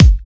kits/OZ/Kicks/K_JustIn.wav at main